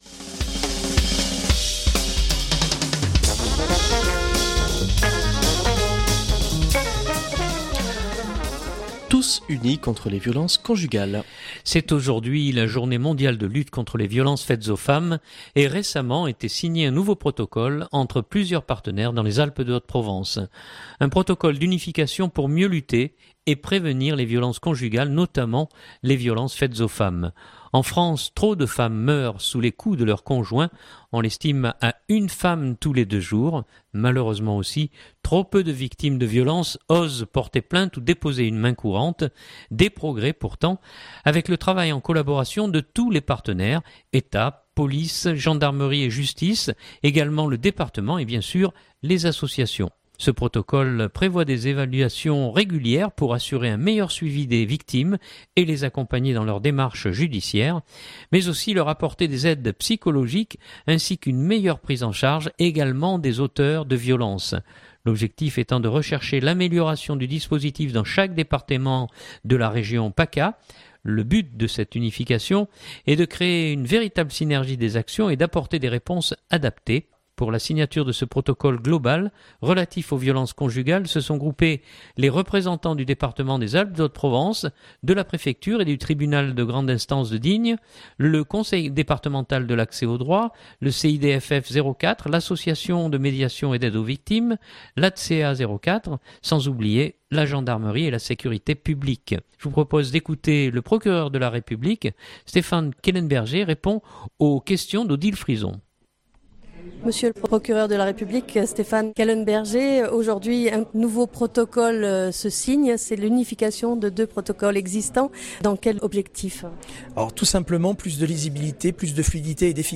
Je vous propose d’écouter le procureur de la République